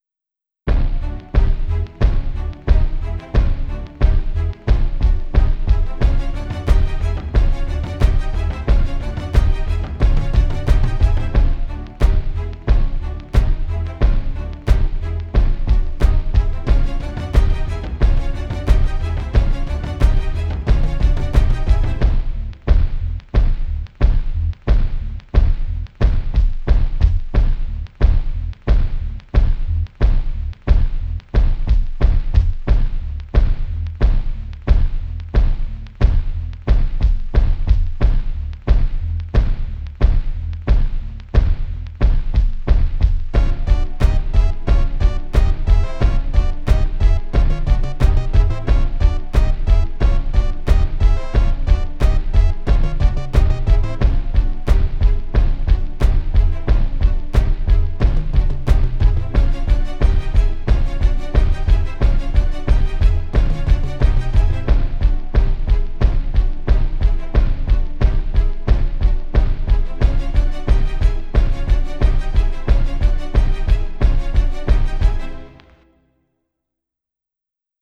full of life and high energy
instrumental songs by design